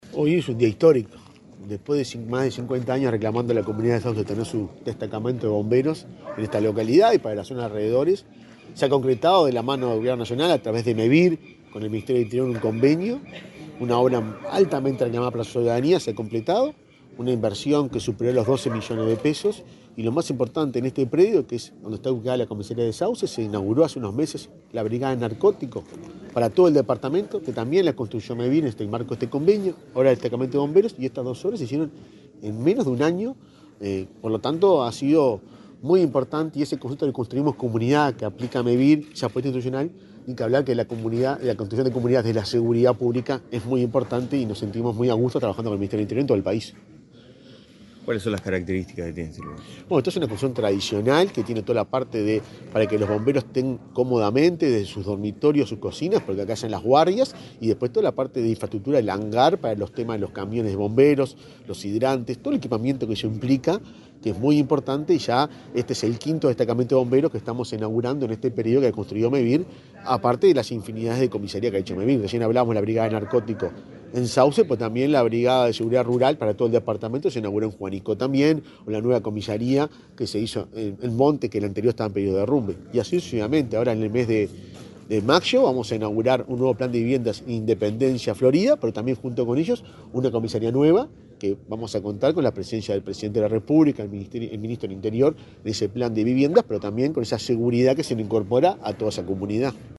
Declaraciones del presidente de Mevir, Juan Pablo Delgado
Antes, el presidente de Mevir, Juan Pablo Delgado, dialogó con la prensa acerca de la participación de ese organismo para concretar el nuevo local.